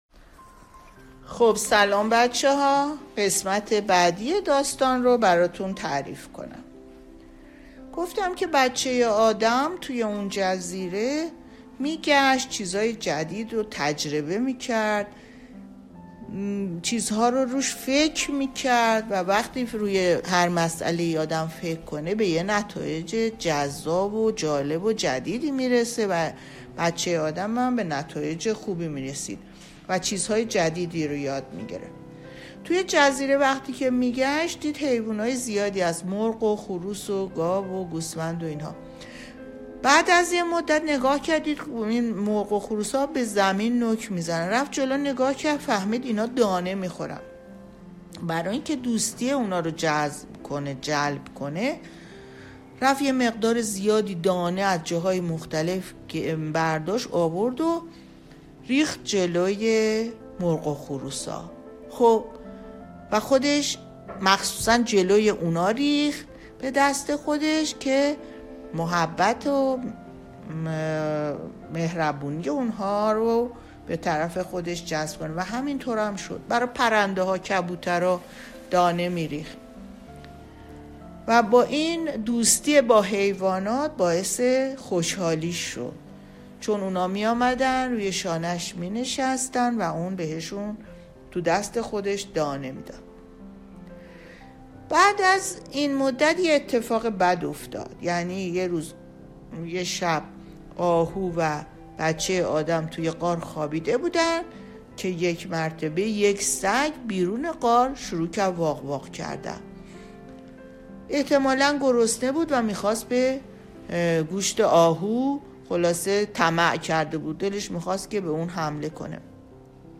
باهم بشنویم ، داستان دنباله دار. قسمت پنجم